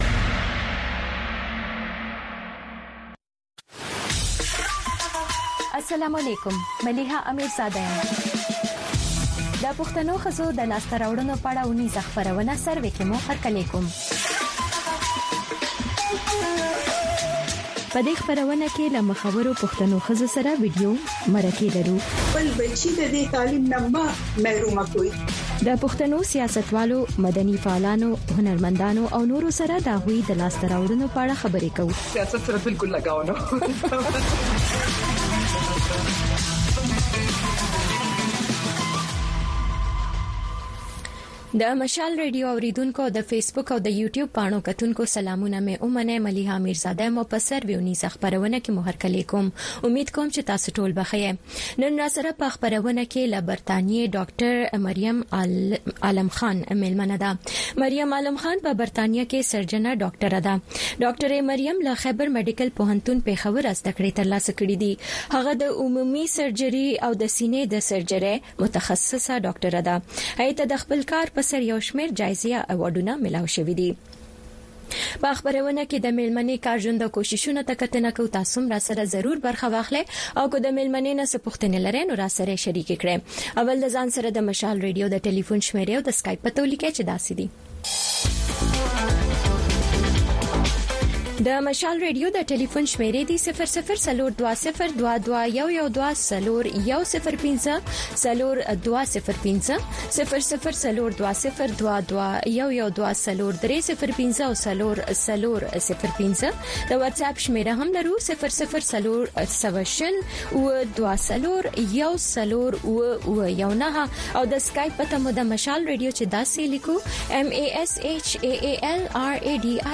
خبرونه
د وی او اې ډيوه راډيو سهرنې خبرونه چالان کړئ اؤ د ورځې دمهمو تازه خبرونو سرليکونه واورئ. په دغه خبرونو کې د نړيوالو، سيمه ايزو اؤمقامى خبرونو هغه مهم اړخونه چې سيمې اؤ پښتنې ټولنې پورې اړه لري شامل دي. دخبرونو په دې جامع وخت کې دسياسياتو، اقتصاد، هنر ، ټنګ ټکور، روغتيا، موسم اؤ لوبو په حقله ځانګړې ورځنۍ فيچرې شاملې دي.